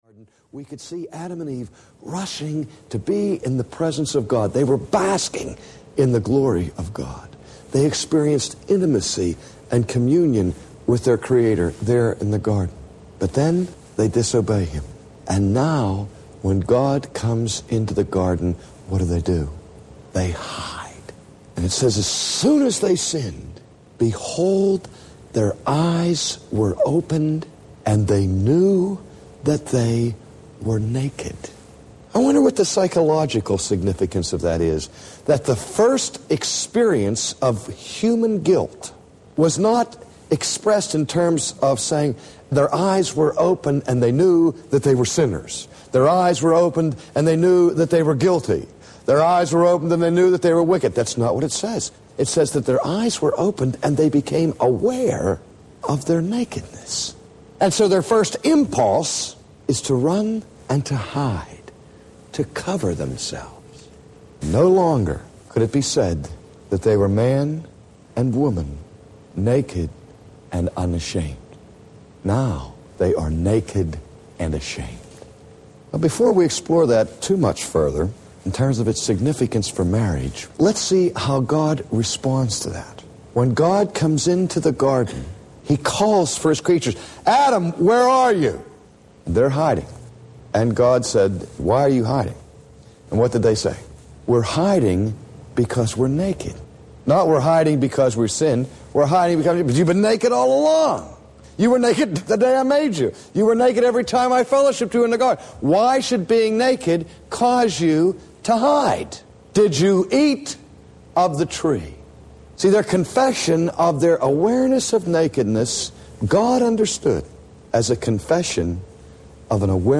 The Intimate Marriage Audiobook
Narrator